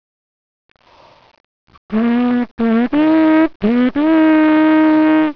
Si pensi che, un trombettista, dovrebbe riuscire a suonare senza bocchino
(clicca qui per sentire due note) oppure con solo il bocchino (clicca qui per sentire le stesse due note) (N.B. sono uno studente per cui i tre suoni non sono ancora perfetti).
labbra.wav